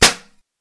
archer_attack3.wav